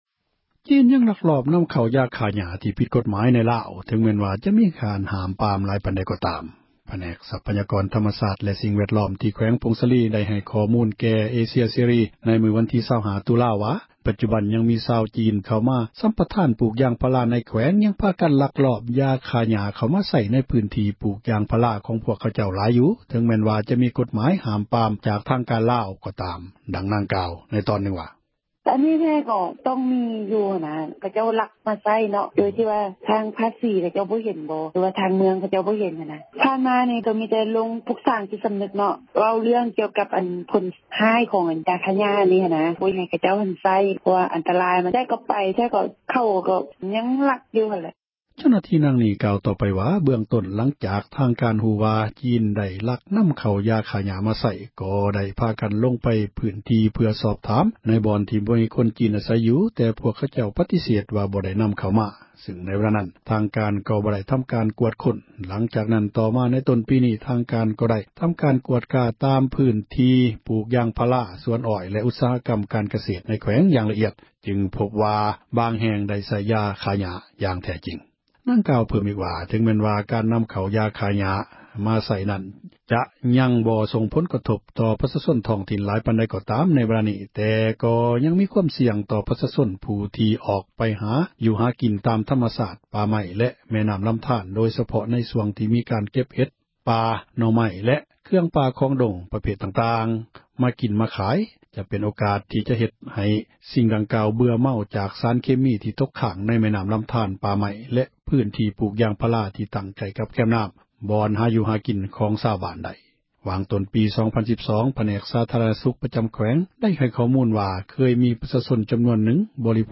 ນາງກ່າວ ໃນຕອນນື່ງວ່າ: